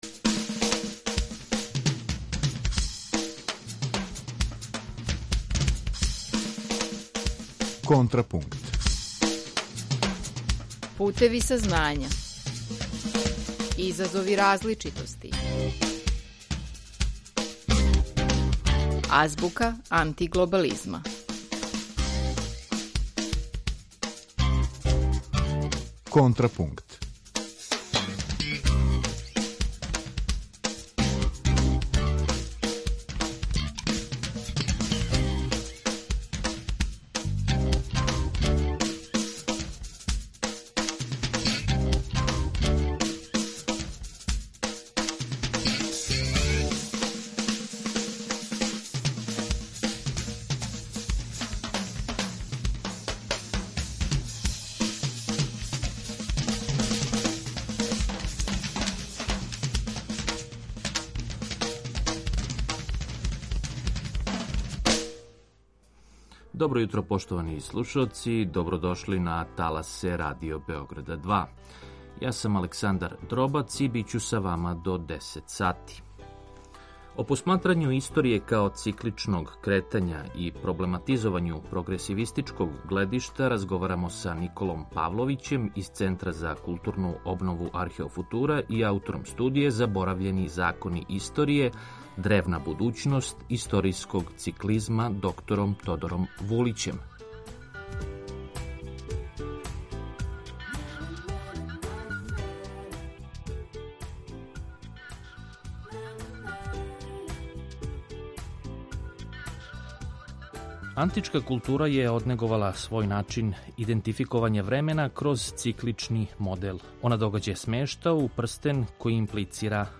О посматрању историје као цикличног кретања, и проблематизовању прогресивистичког гледишта разговарамо